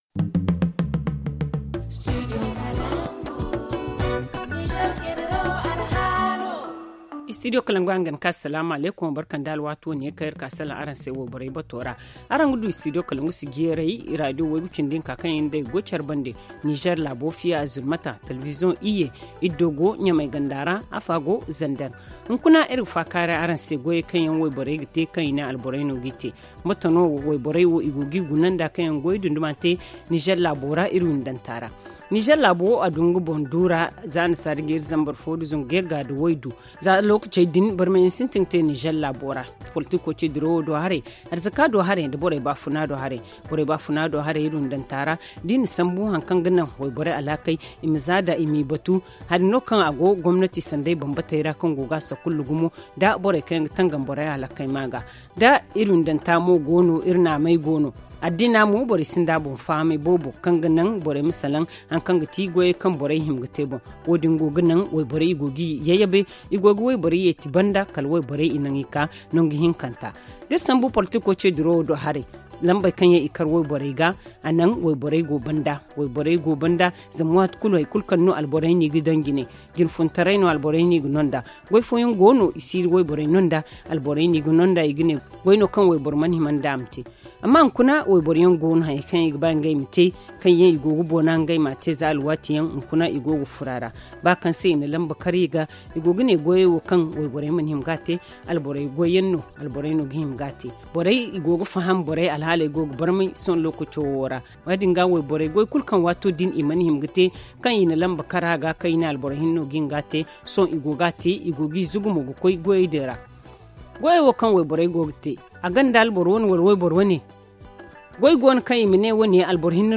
ZA Le forum en zarma https